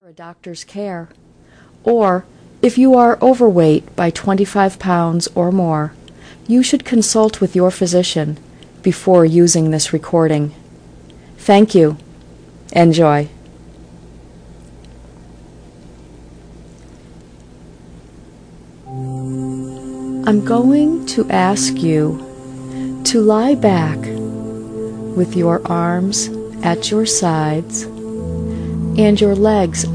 30-minute mp3 recording with dream-like music to listen to before bedtime.